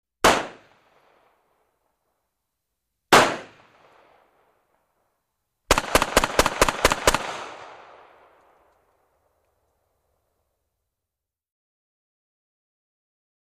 Ppk 380 Hand Gun Shots, From Interior Perspective.